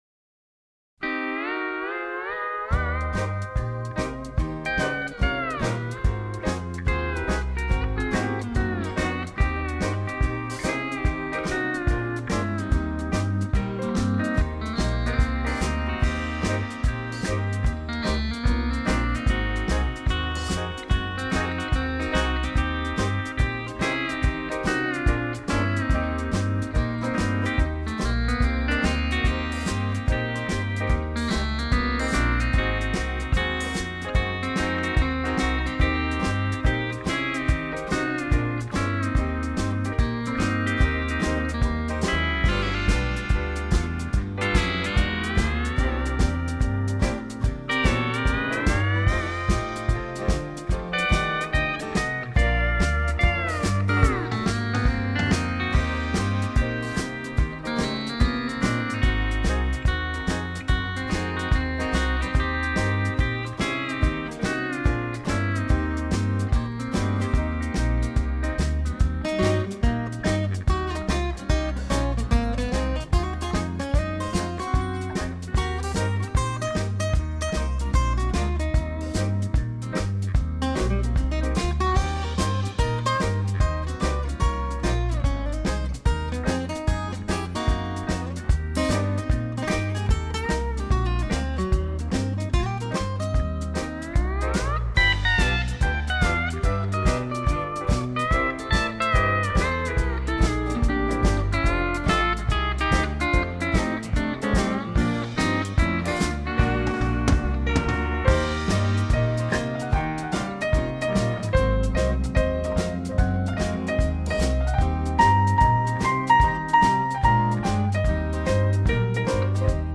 Right, it's like the D6th position on E9th.
in Eb